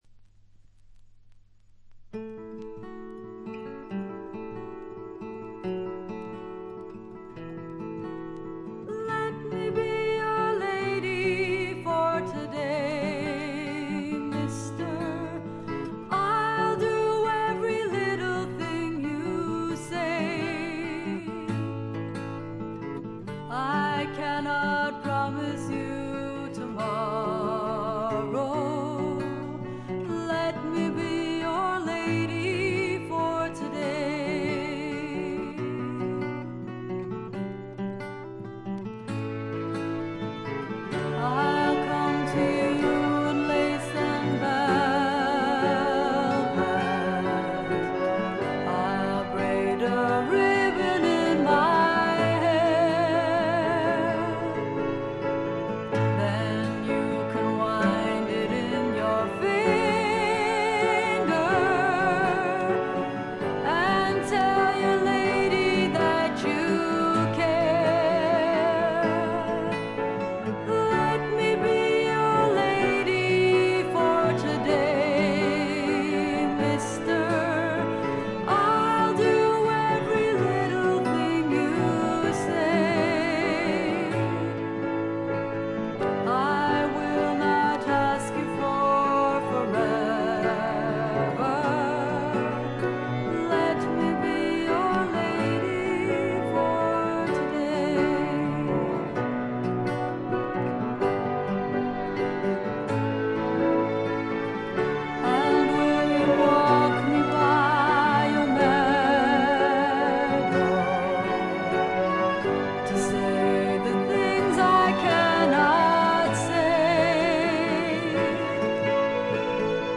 他は軽微なチリプチが少々。
試聴曲は現品からの取り込み音源です。